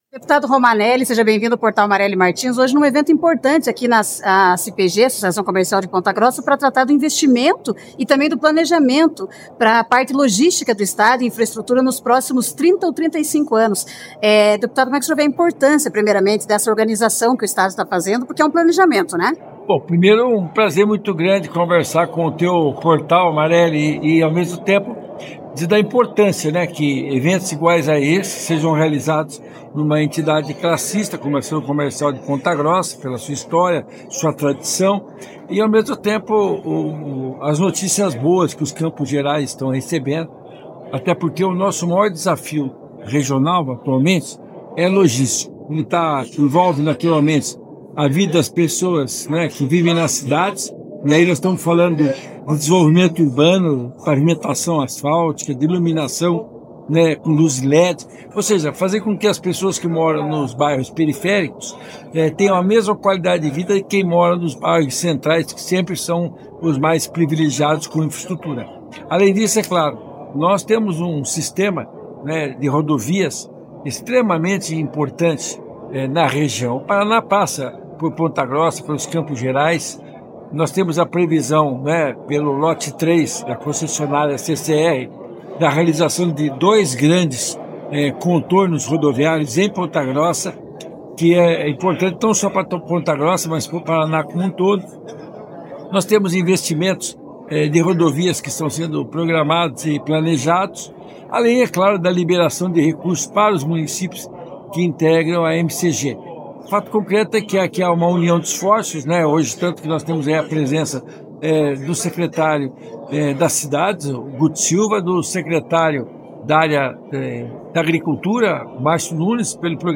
“Começamos as audiências públicas por Ponta Grossa, mas este plano, que mostra as obras já em andamento e os investimentos que ainda virão, se estenderá a todas as regiões observando as potencialidades de desenvolvimento e fundamentalmente garantir o escoamento das safras e a circulação dos bens de produção e serviços do estado a outros centros e mercados de consumo”, disse Romanelli no lançamento do plano na Associação Comercial, Industrial e Empresarial de Ponta Grossa.
Ouça a declaração de Romanelli